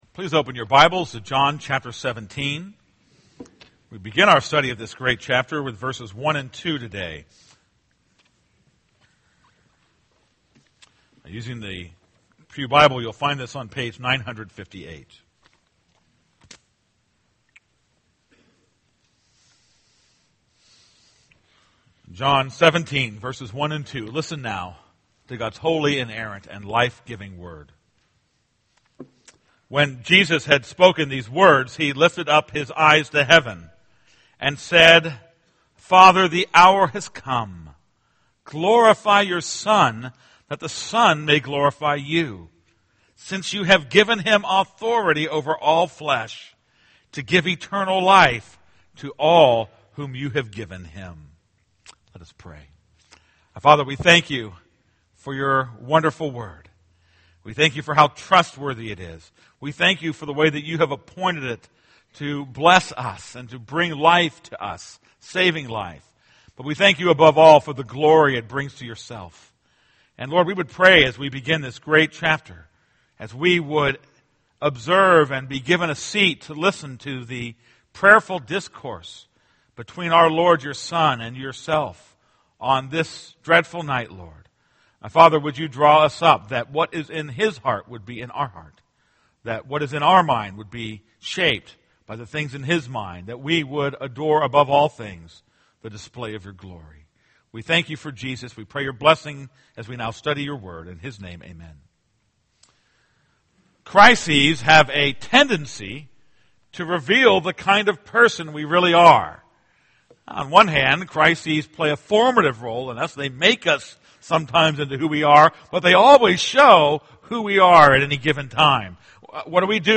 This is a sermon on John 17:1-2.